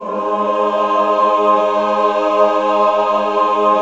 Index of /90_sSampleCDs/USB Soundscan vol.28 - Choir Acoustic & Synth [AKAI] 1CD/Partition B/06-MENWO CHD